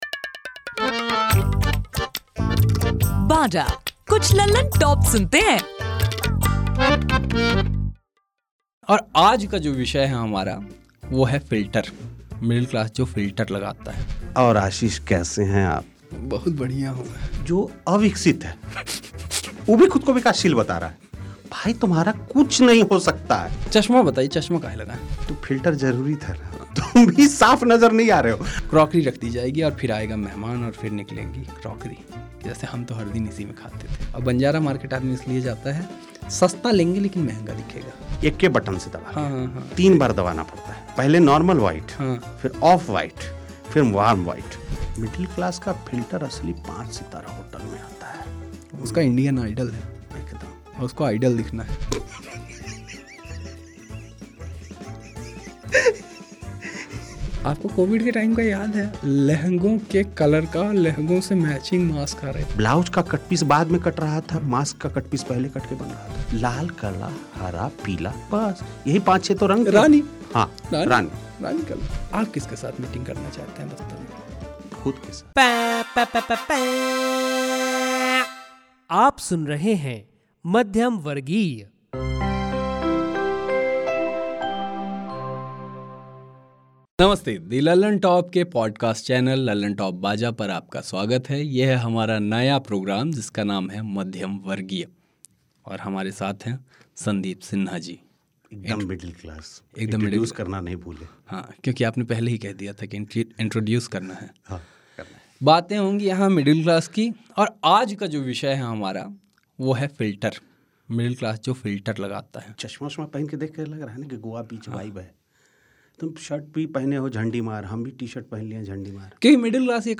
लल्लनटॉप का पॉडकास्ट चैनल लल्लनटॉप बाजा आपके लिए लाया है मिडिल क्लास लोगों और उनकी मज़ेदार आदतों पर बातचीत का सस्ता टिकाऊ शो 'मध्यमवर्गीय'.